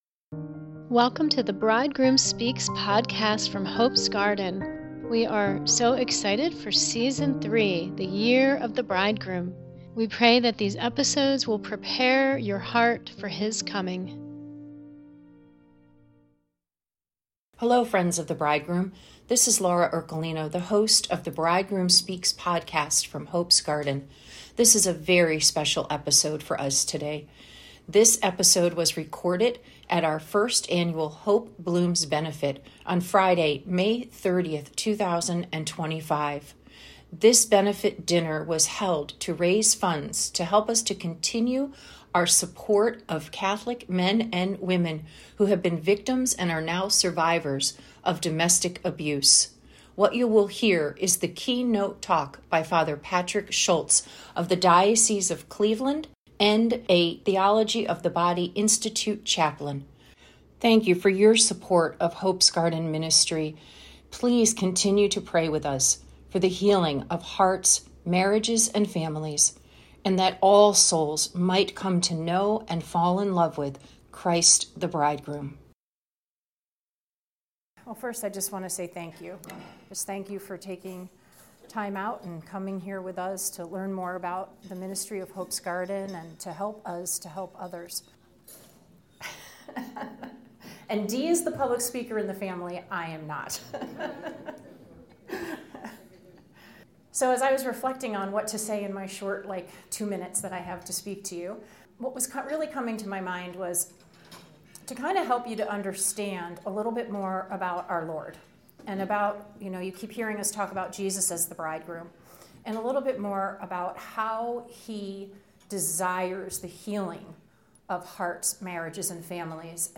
Our conversation was so inspiring.